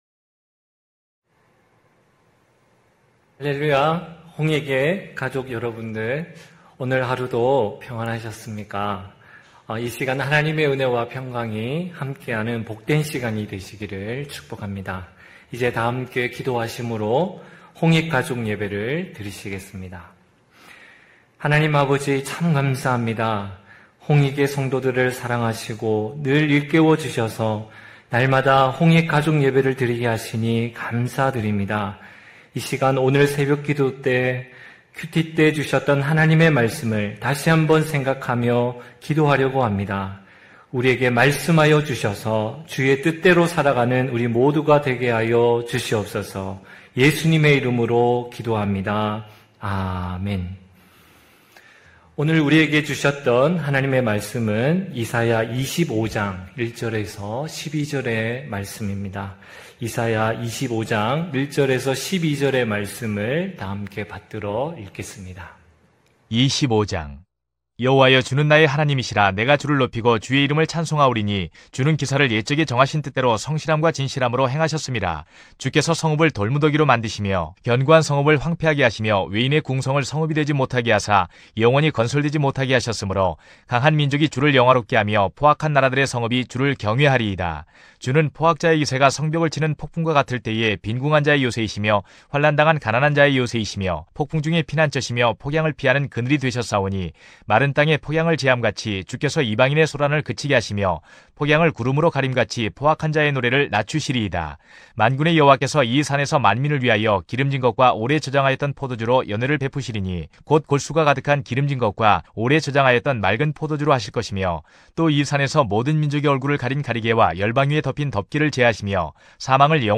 9시홍익가족예배(8월13일).mp3